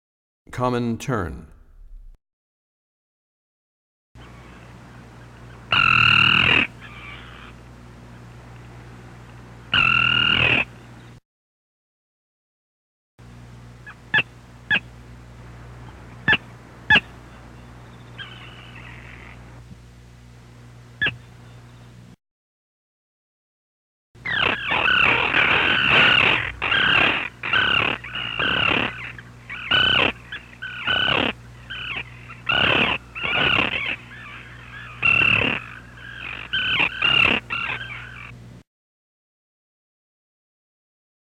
27 Common Tern.mp3